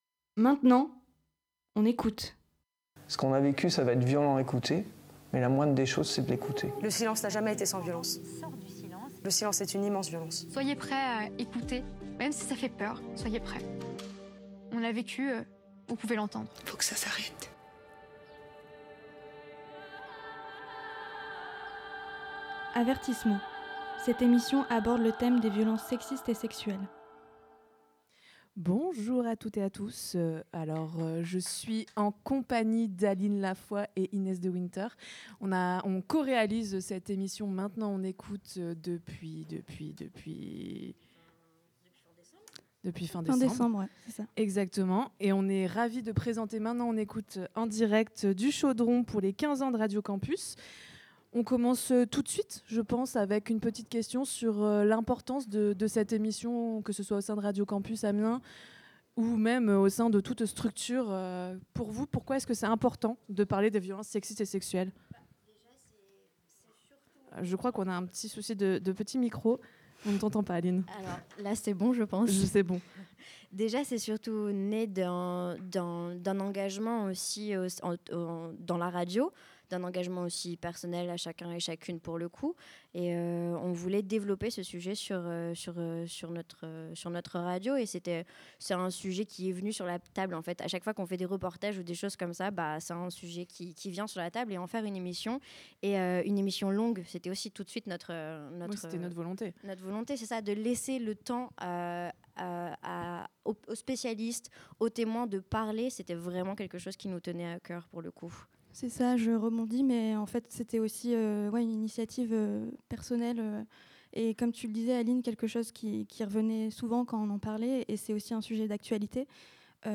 Samedi 22 mars, les animateurs et animatrices Radio Campus sur scène au Chaudron - Scène étudiante du Crous et en direct !
L’équipe de Maintenant, on écoute ! sur scène : les violences et le harcèlement sexistes et sexuels sont des questions trop sérieuses pour être ignorées durant l’anniversaire de Radio Campus Amiens.